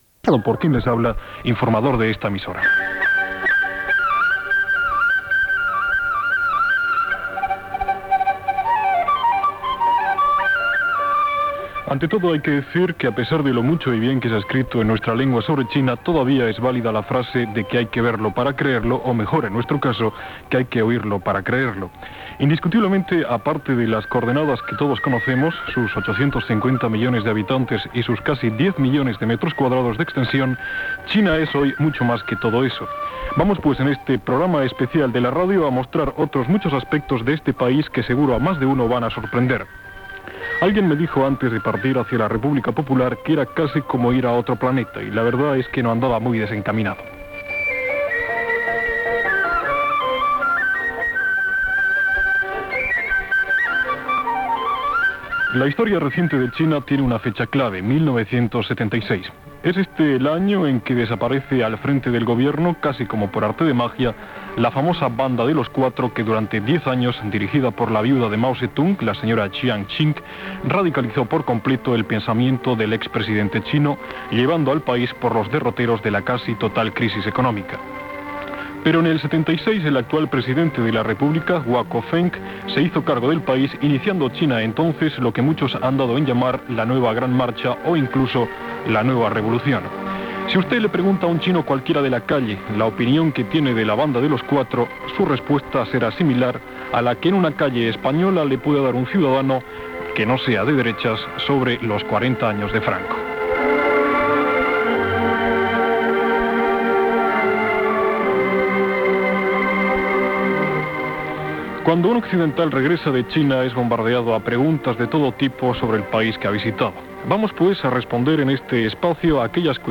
El reportatge es va fer durant un viatge a Xina els mesos d'abril i maig del 1979.